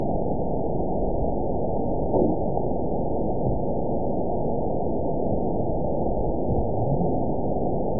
event 920397 date 03/22/24 time 23:59:33 GMT (1 year, 1 month ago) score 7.74 location TSS-AB02 detected by nrw target species NRW annotations +NRW Spectrogram: Frequency (kHz) vs. Time (s) audio not available .wav